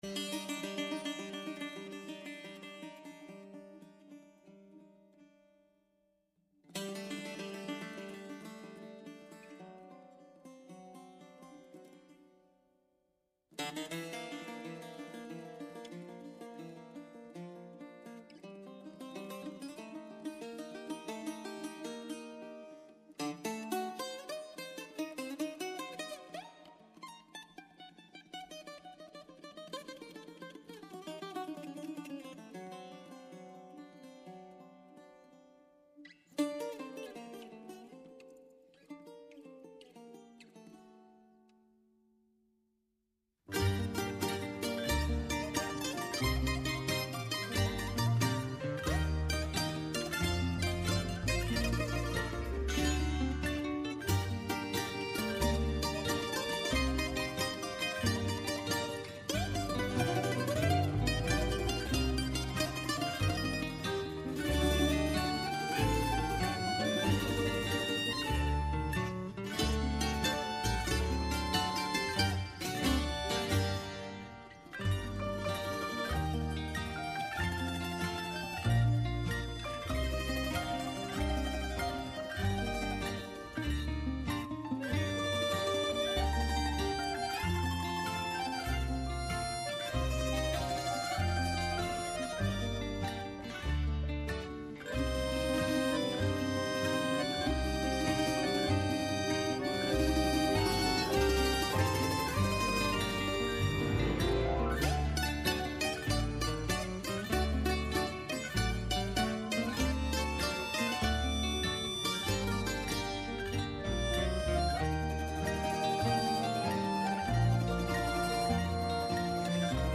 ΔΕΥΤΕΡΟ ΠΡΟΓΡΑΜΜΑ Μουσική Συνεντεύξεις